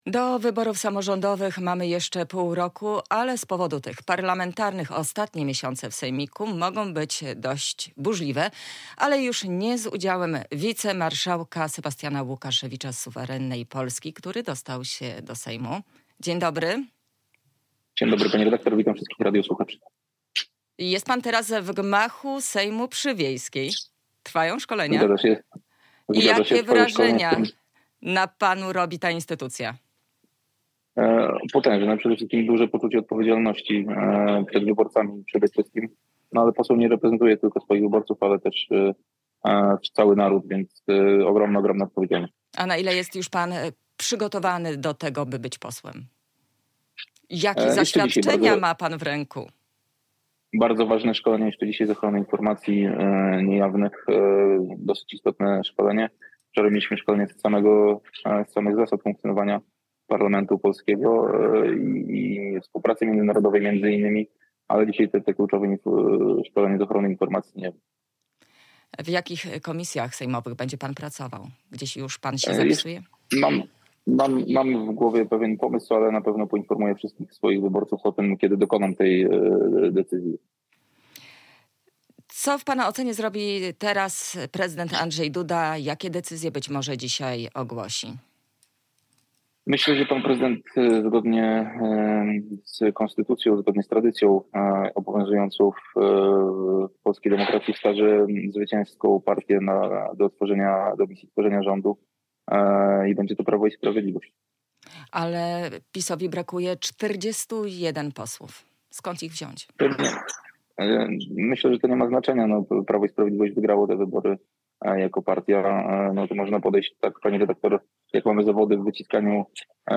nowo wybrany poseł z Suwerennej Polski